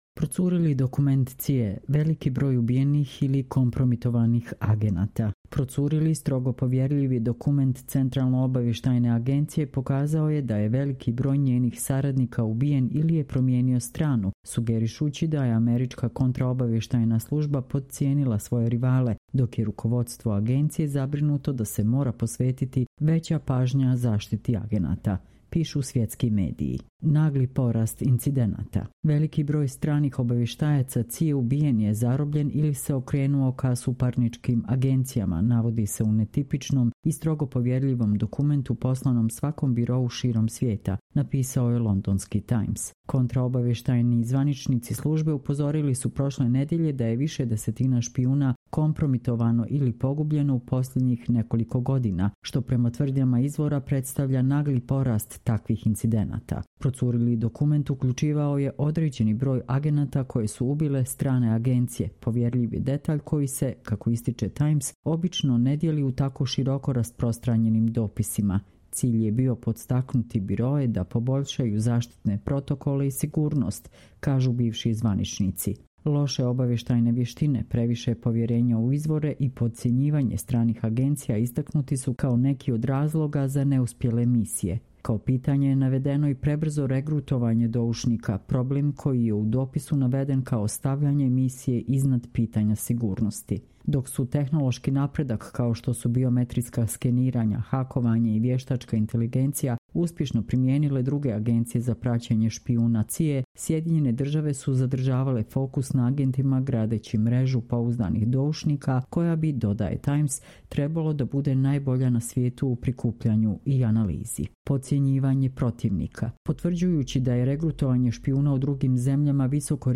Čitamo vam: Procurili dokument CIA - veliki broj ubijenih ili kompromitovanih agenata